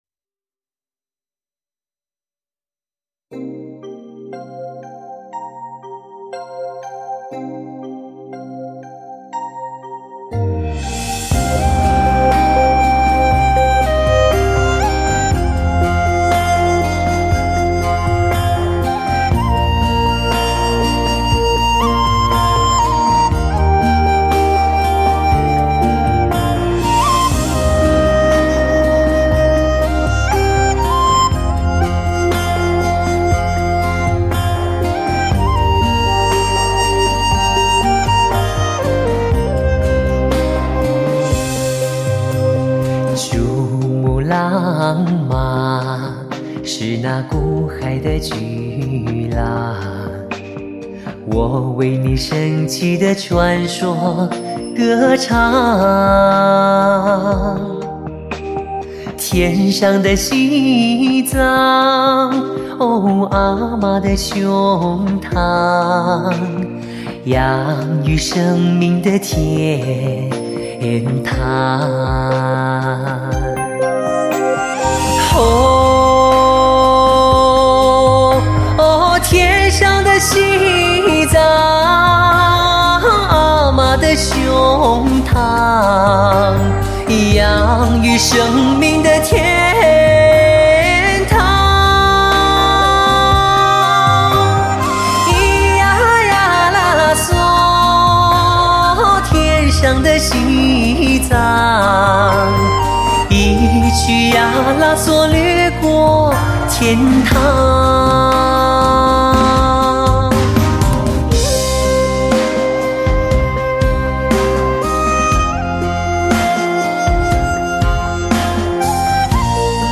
极致发烧的民族靓嗓 绿色草原的天籁之歌
令人无限陶醉的悠扬旋律 直袭心驰神往的醉美发烧境界